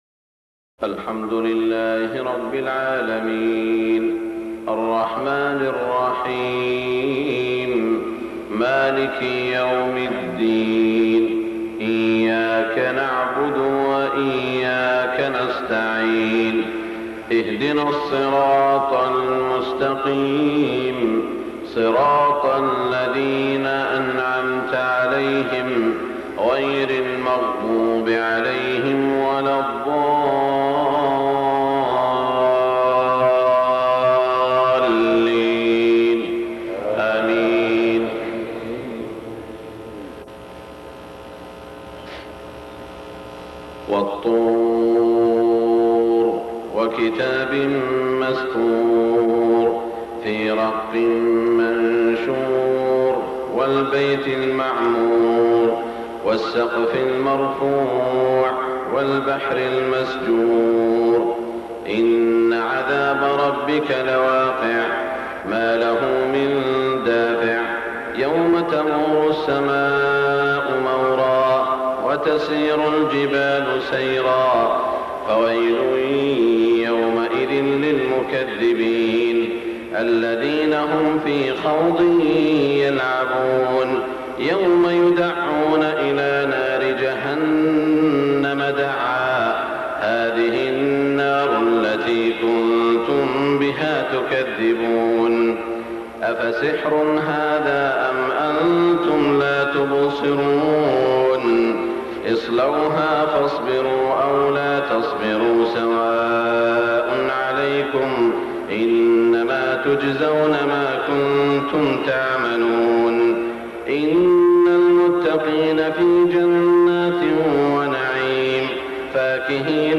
صلاة الفجر 1424 تلاوة لسورة الطور > 1424 🕋 > الفروض - تلاوات الحرمين